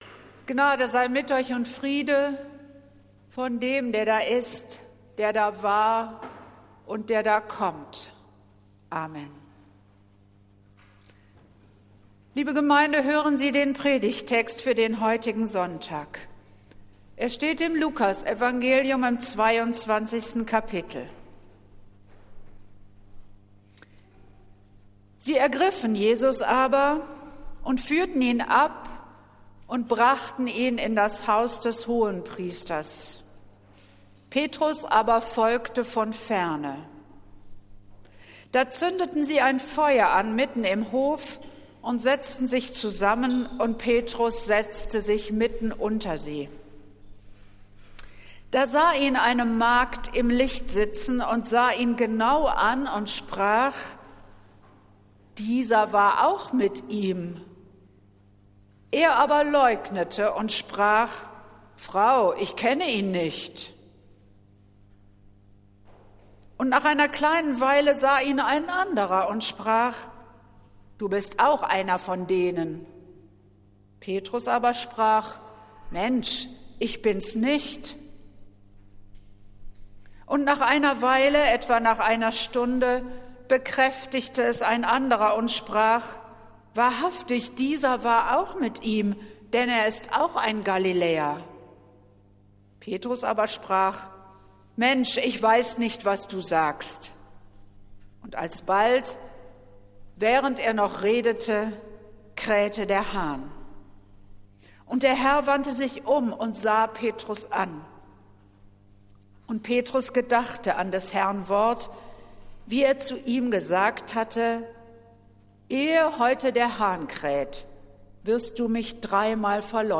Predigt des Gottesdienstes aus der Zionskirche am Sonntag, den 10. März 2023